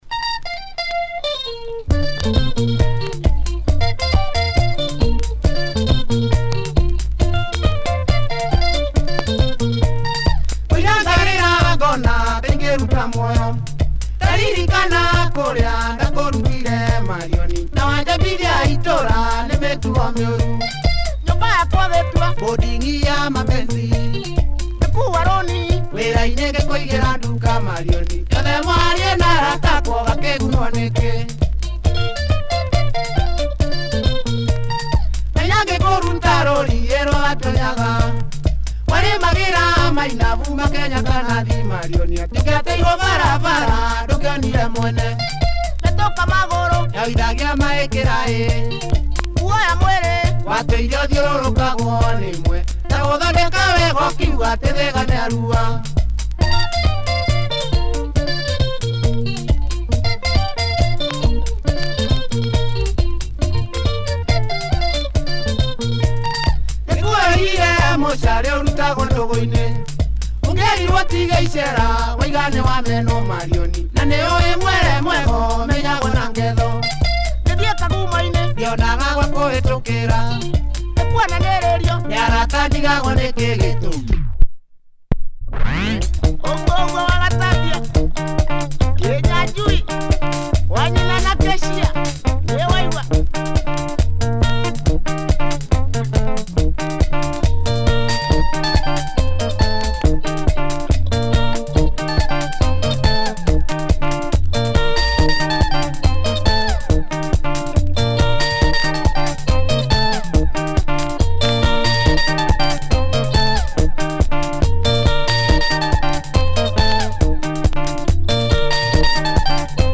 Nice driving modern Kikuyu Benga, b-side has Female vocals.